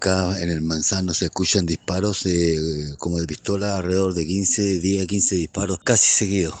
Un auditor lo alertó a la radio segundos luego de la balacera.